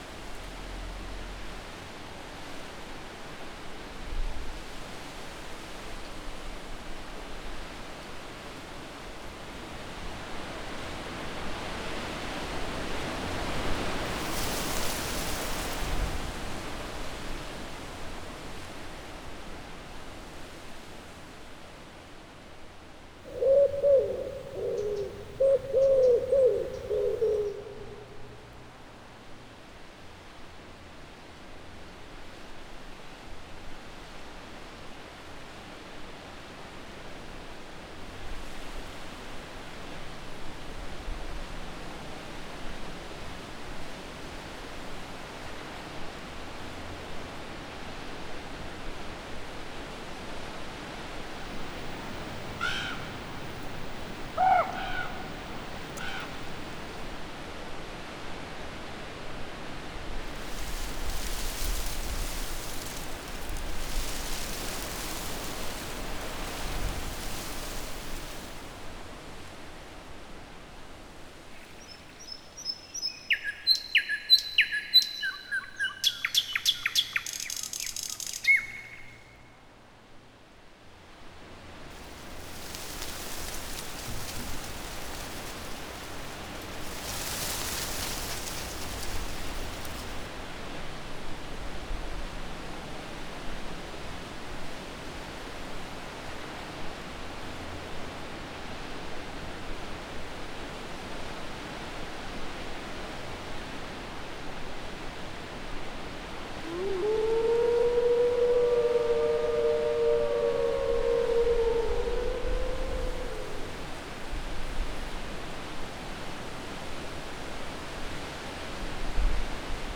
Farm_Atmos_06.wav